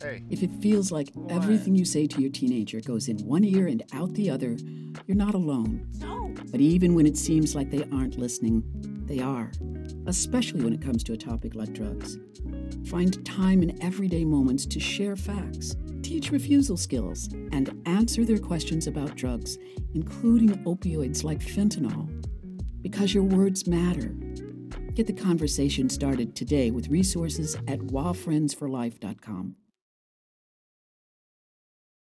Talk to Teens Audio PSA